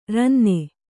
♪ ranne